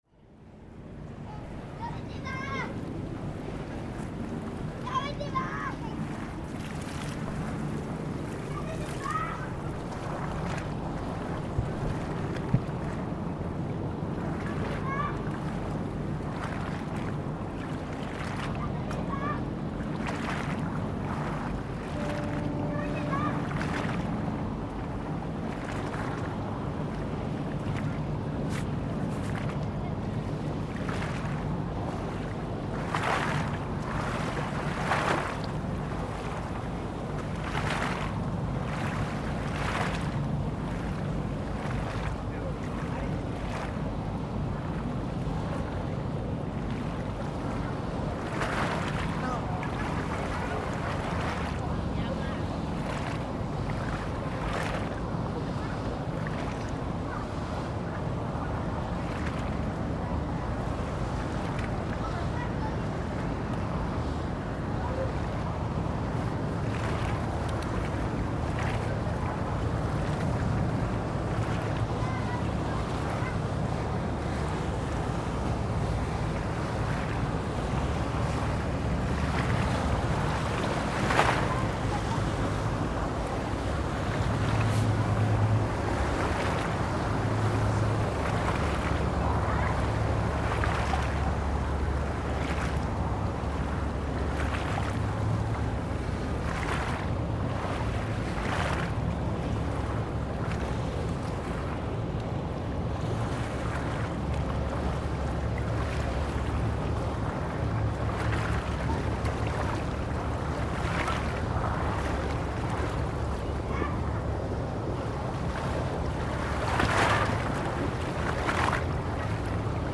Boats and buskers in Venice
Venice is the spiritual home of Cities and Memory, and one of our favourite places to record - the soundscapes are so pure and traffic-free, and Venice sounds like nowhere else.
Here we're facing out towards the Giudecca, and tuning in to the sounds of passing boats and an accordion busker off to our left.